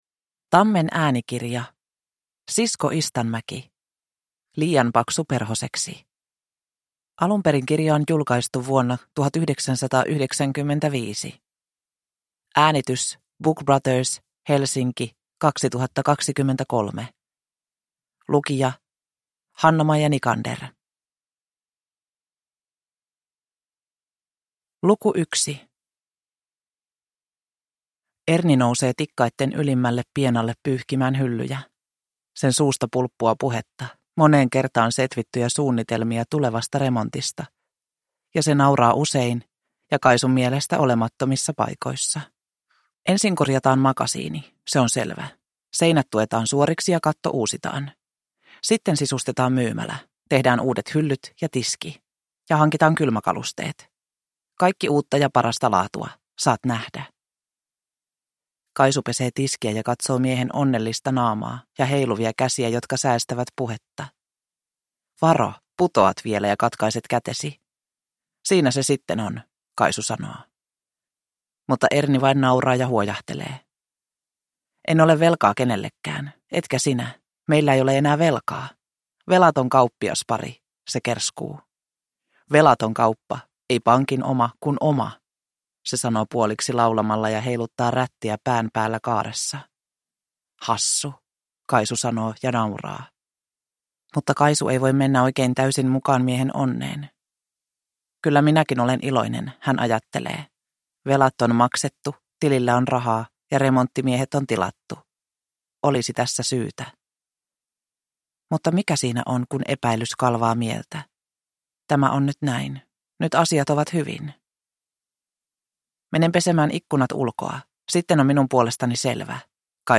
Liian paksu perhoseksi – Ljudbok